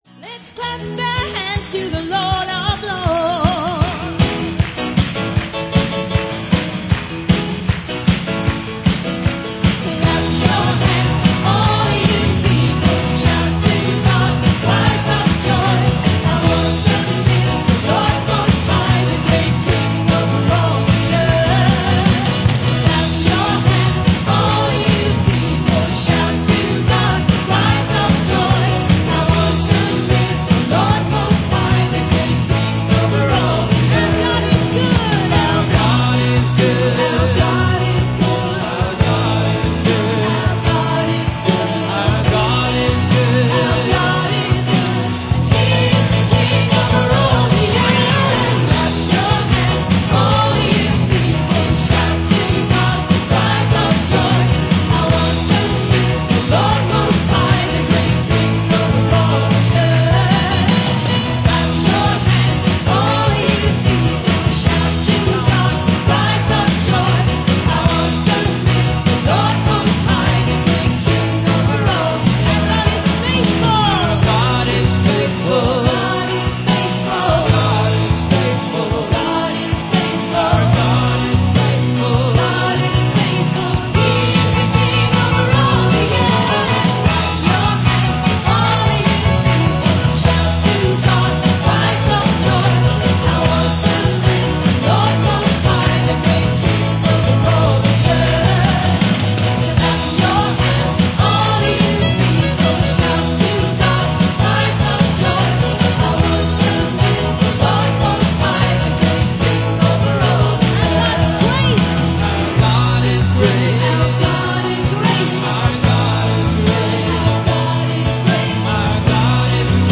Good rouser